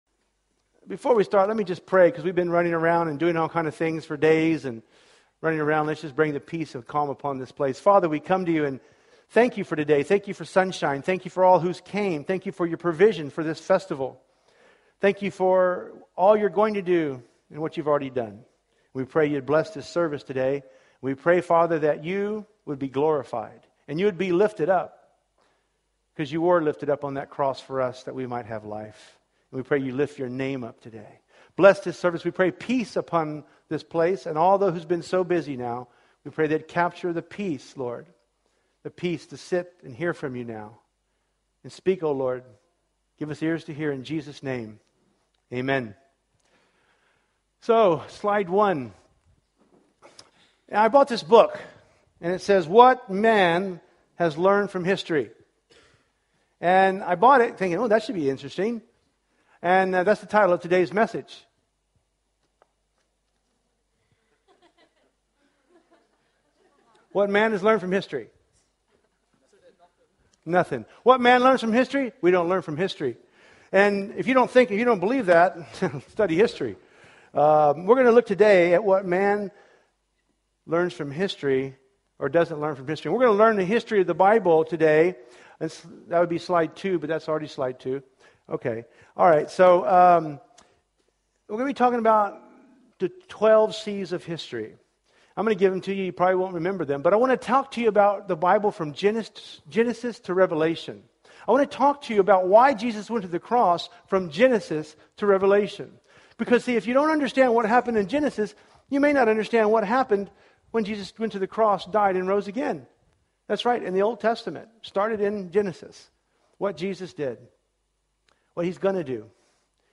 What Men Learn from History – Easter Sunday Service